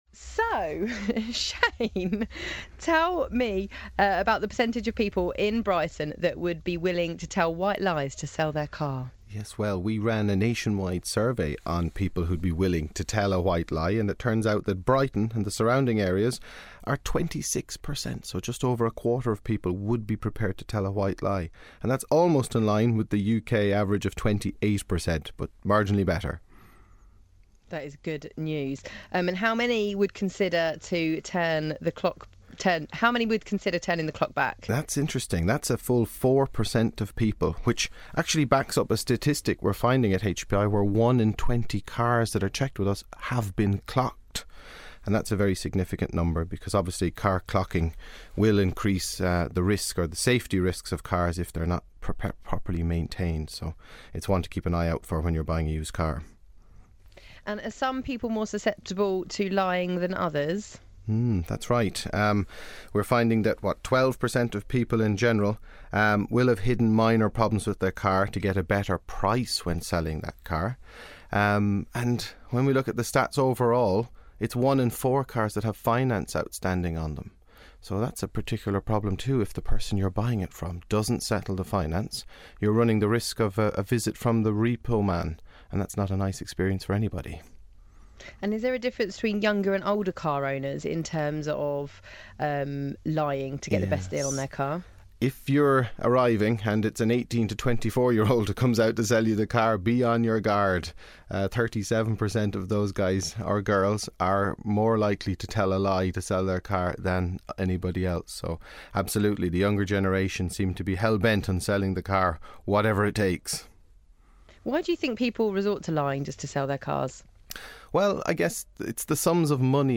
Interview with Juice 107.2 Radio